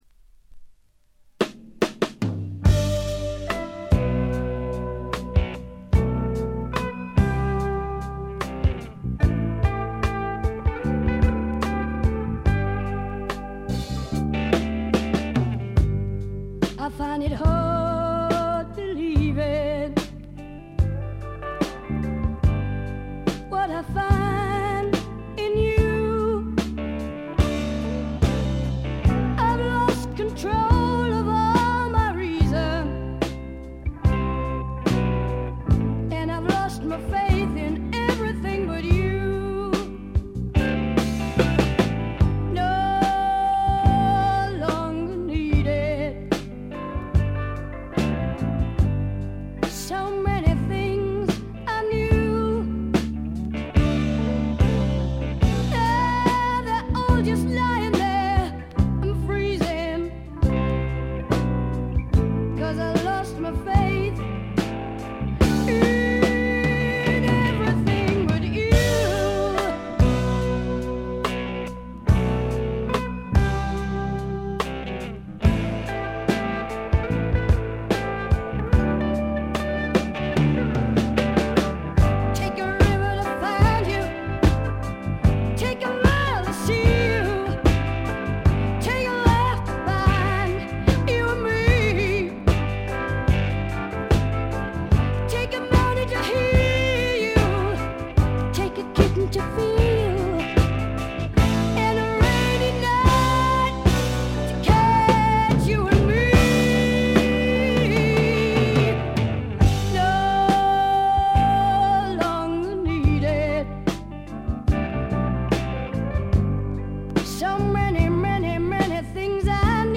ホーム > レコード：英国 スワンプ
録音はロンドンとナッシュビルで録り分けています。
試聴曲は現品からの取り込み音源です。